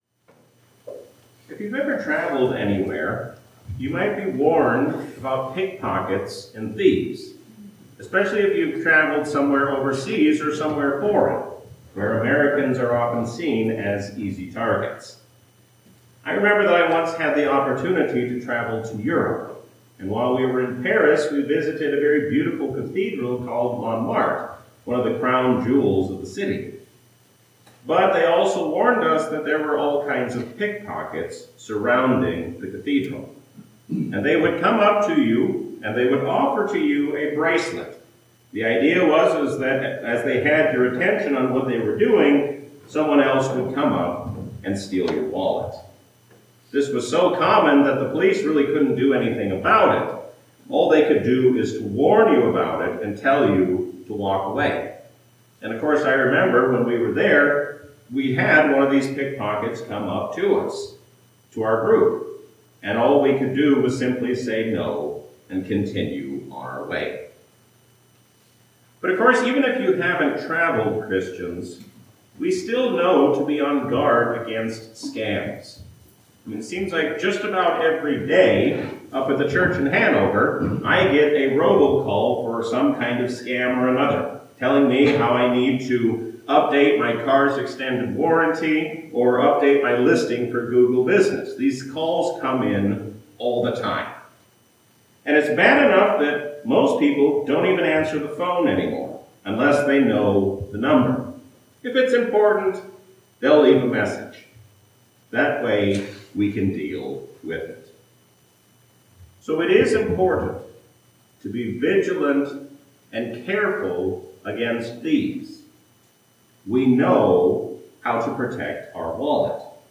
A sermon from the season "Christmas 2024." Jesus is the reason for the season because He has come to be our Brother and our Savior.